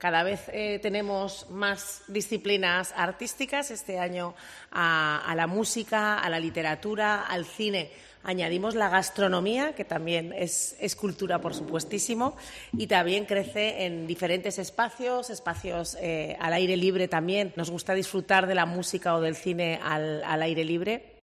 La vicealcaldesa de Zaragoza, Sara Fernández, habla de las III jornadas sobre la cultura francesa en Zaragoza.